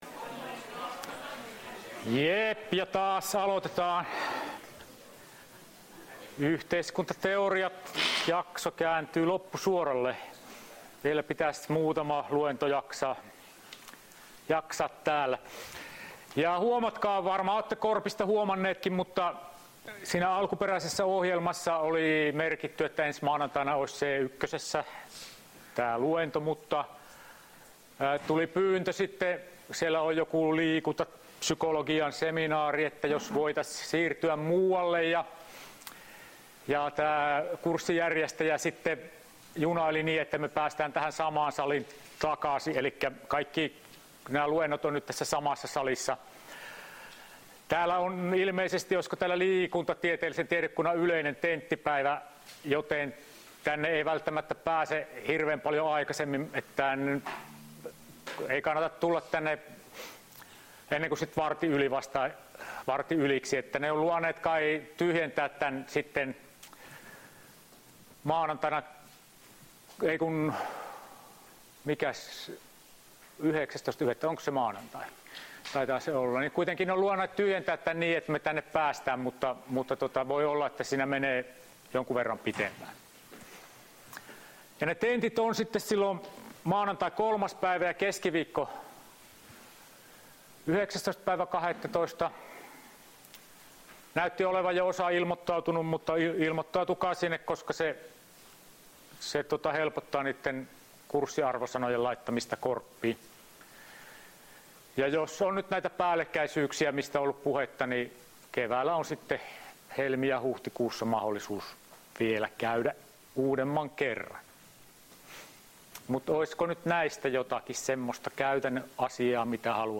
Luento 14.11.2018